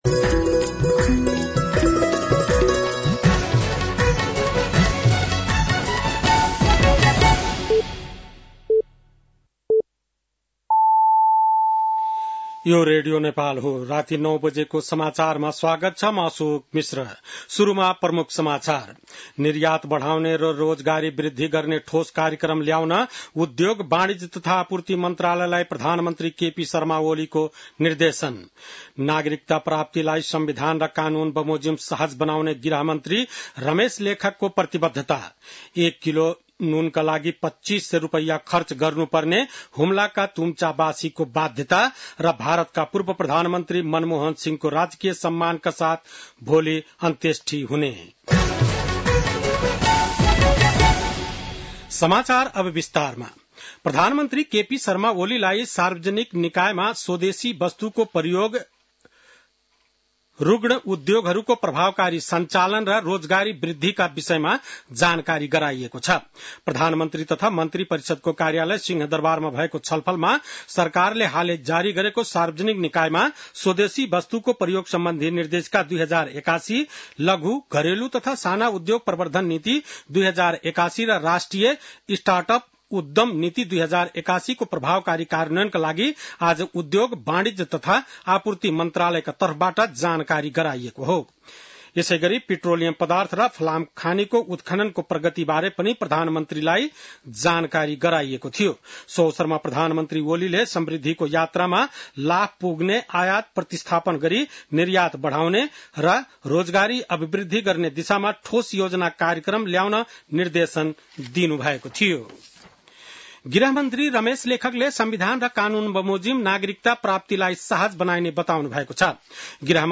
बेलुकी ९ बजेको नेपाली समाचार : १४ पुष , २०८१